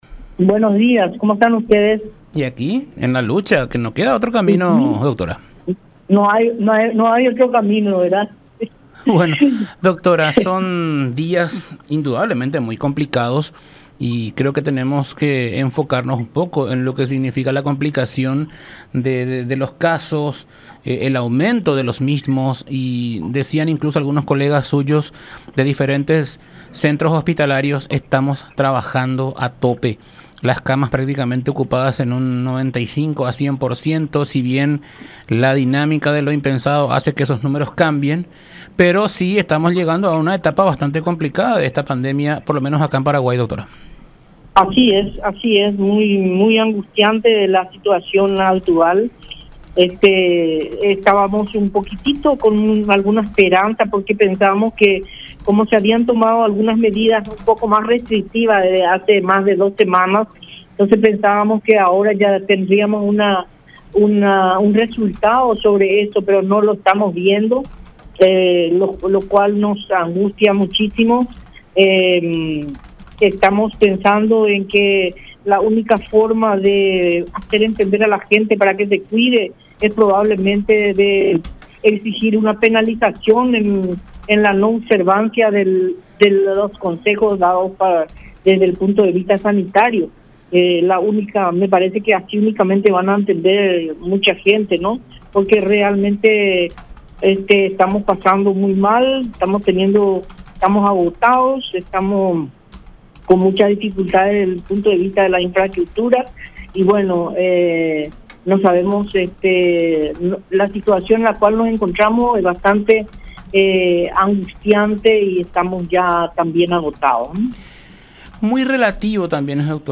en comunicación con La Unión R800 AM